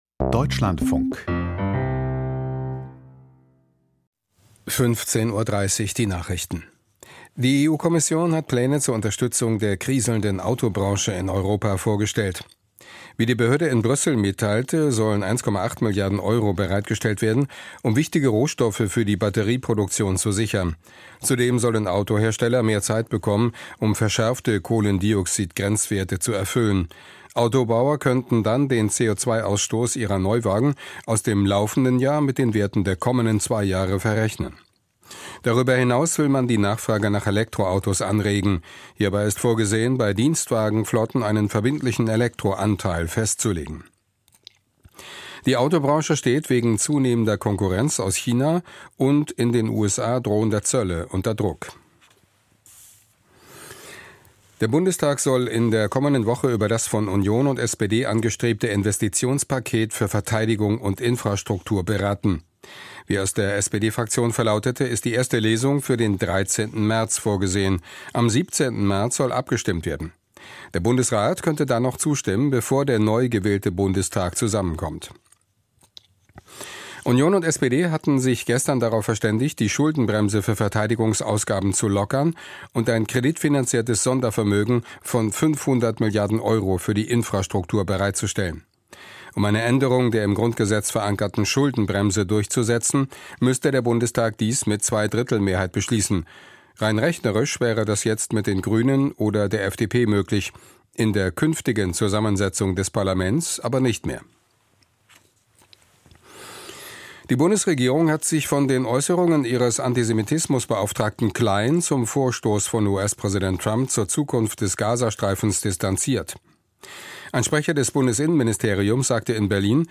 Die Deutschlandfunk-Nachrichten vom 05.03.2025, 15:30 Uhr